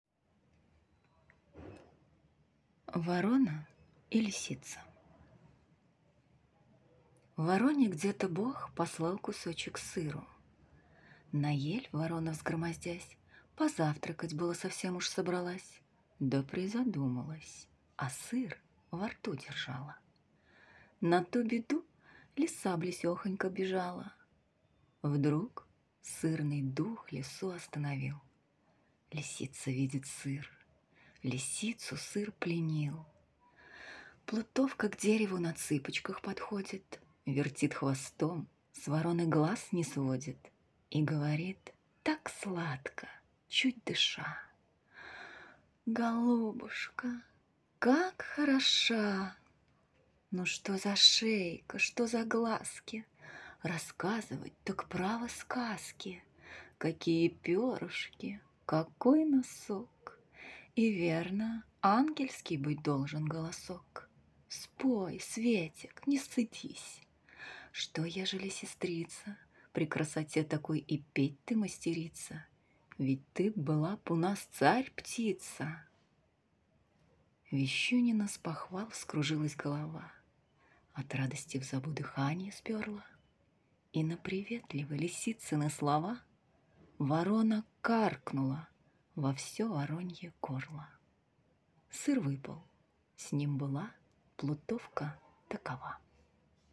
Жен, Аудиокнига/Молодой